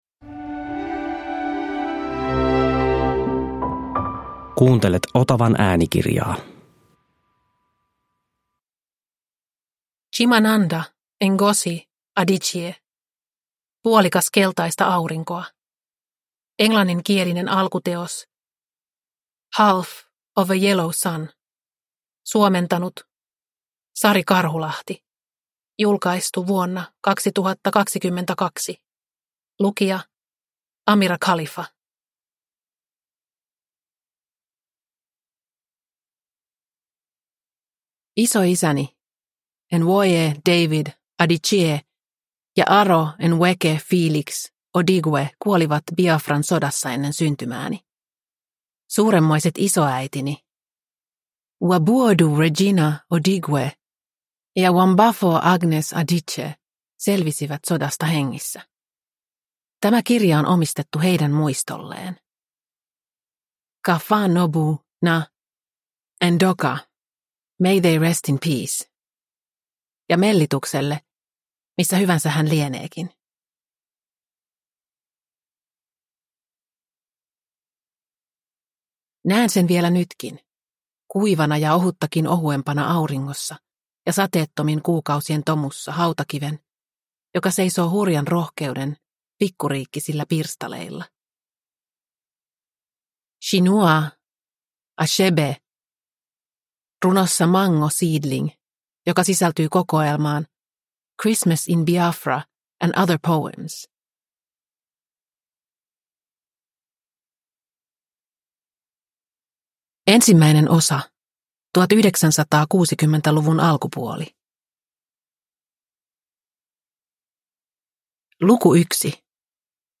Puolikas keltaista aurinkoa – Ljudbok – Laddas ner